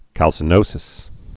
(kălsə-nōsĭs)